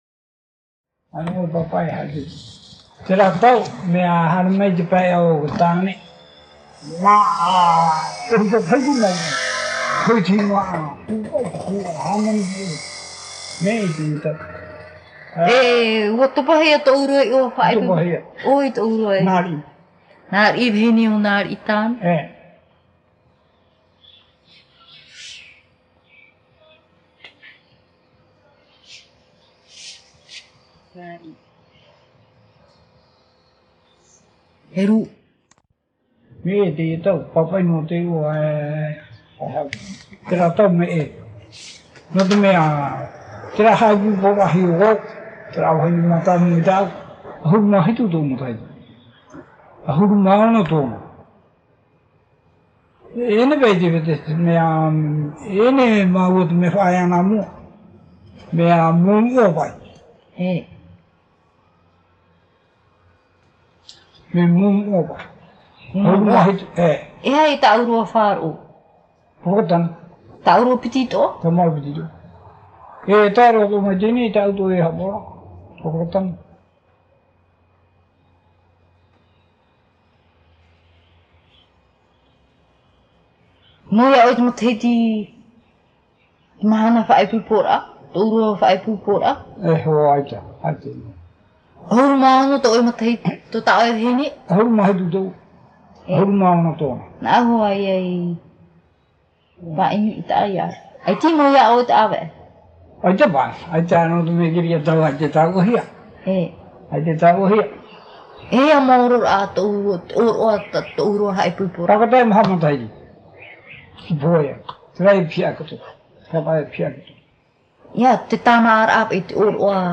Descriptif de l'interview
Interview réalisé à Arue sur l’île de Tahiti.
Papa mātāmua / Support original : cassette audio
SEE0003_Vocal-Isolation.mp3